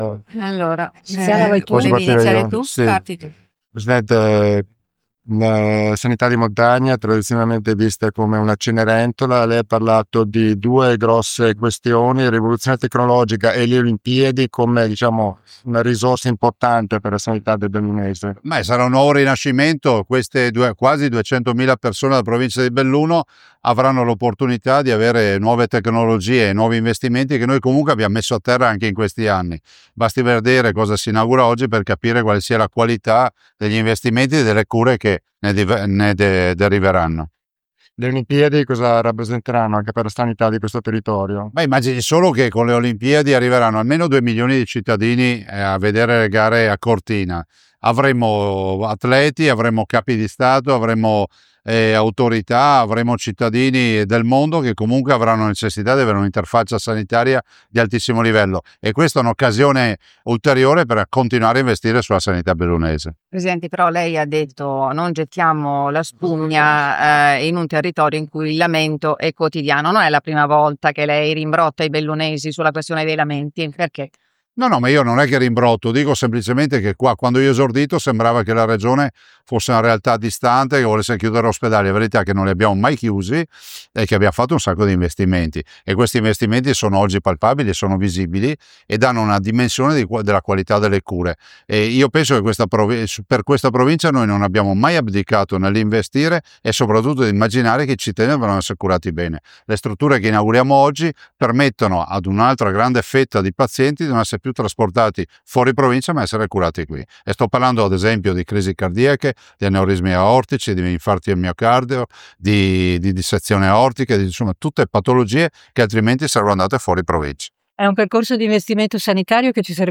ZAIA A BELLUNO, INAUGURAZIONE E PUNTO STAMPA
BELLUNO Oggi alle 11.30, il Presidente della Regione Veneto, Luca Zaia, era a Belluno in occasione dell’inaugurazione della sala ibrida e della sala di emodinamica dell’Ospedale San Martino.